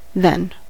than-unstressed: Wikimedia Commons US English Pronunciations
En-us-than-unstressed.WAV